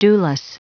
Prononciation du mot dewless en anglais (fichier audio)
Prononciation du mot : dewless